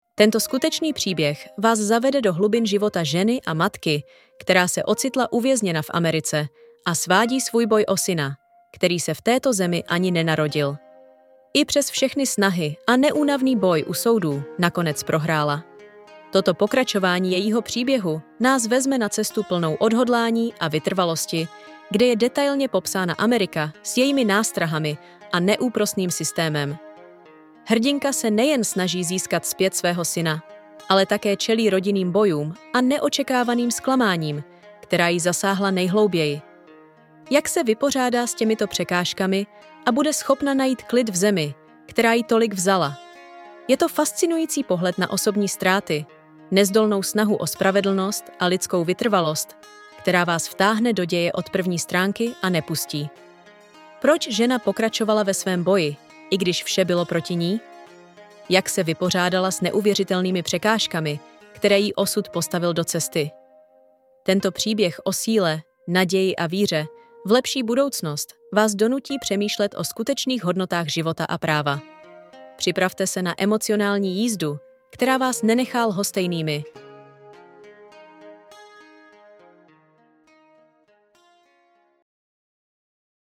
Životní příběh striptérky 2 – audiobook – 249 Kč
Ukázka z úvodu knihy